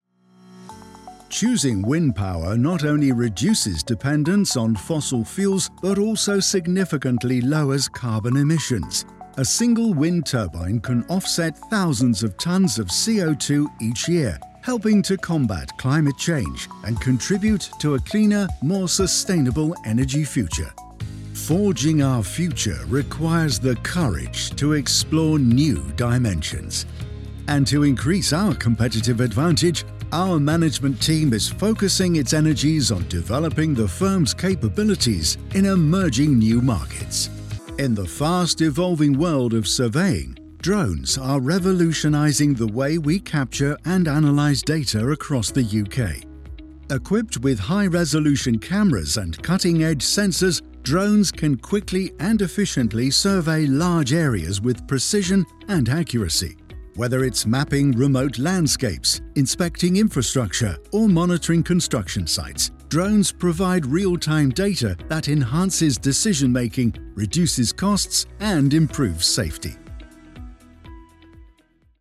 Male
Older Sound (50+)
With over a decade of experience, I have a warm, trustworthy, and versatile British male voice with a natural RP accent.
Broadcast-quality audio from a professional home studio with fast turnaround and seamless delivery.
Corporate Reel